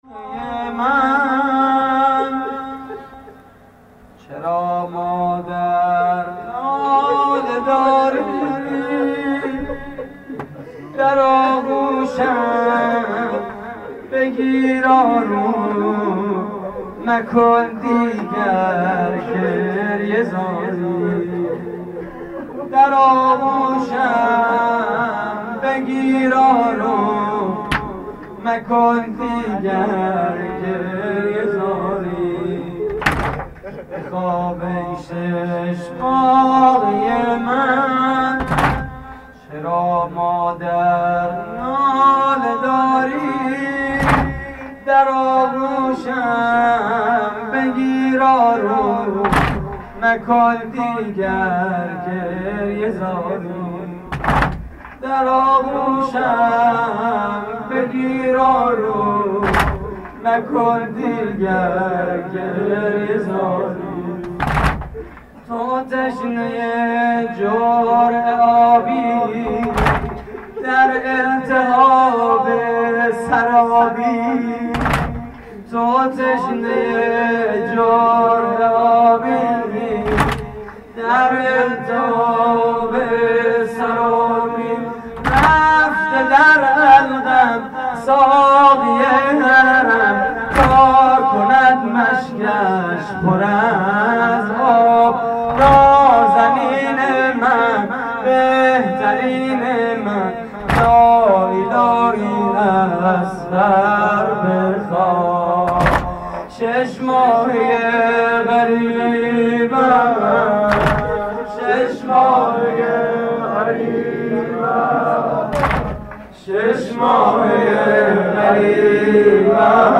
واحد: چرا مادر ناله داری متاسفانه مرورگر شما، قابیلت پخش فایل های صوتی تصویری را در قالب HTML5 دارا نمی باشد.
مراسم عزاداری شب هفتم محرم 1432